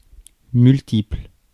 Ääntäminen
France: IPA: [myl.tipl]